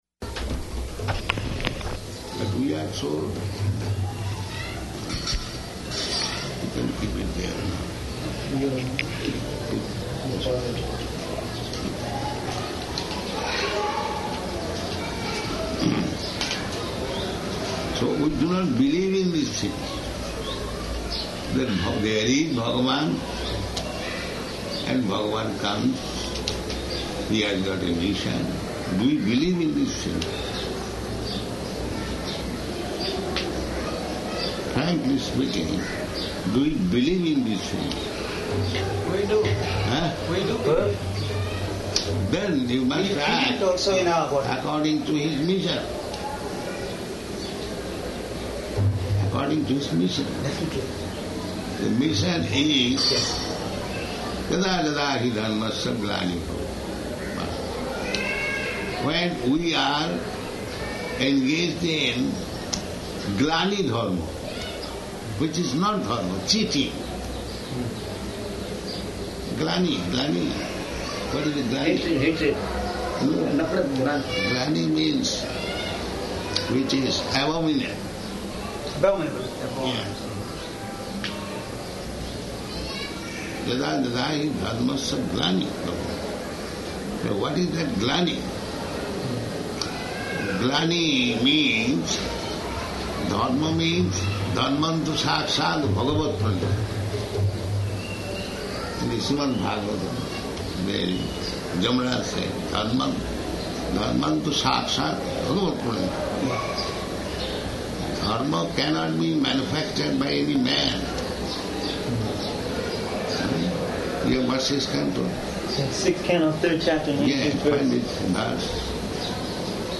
Meeting with Mr. Raju, Endowments Commissioner --:-- --:-- Type: Conversation Dated: August 24th 1976 Location: Hyderabad Audio file: 760824R1.HYD.mp3 Prabhupāda: But we are so...